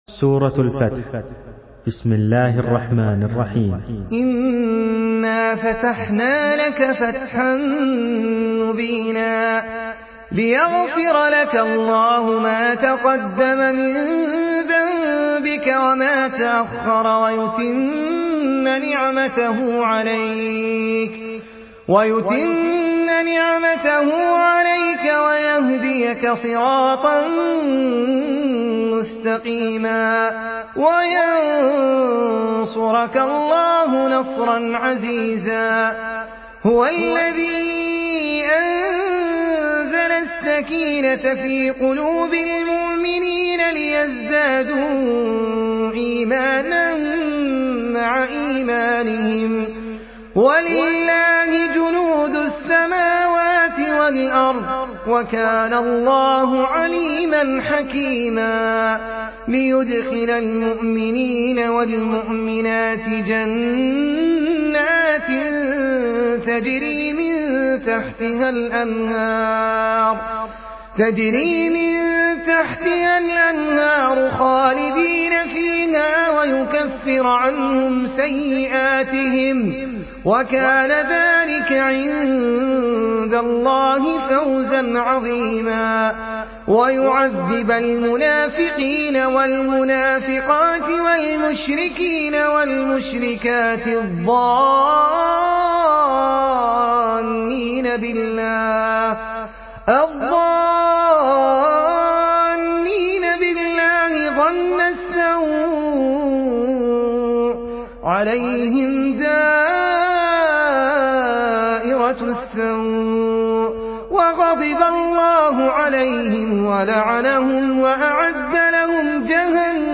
Surah الفتح MP3 by أحمد العجمي in حفص عن عاصم narration.
مرتل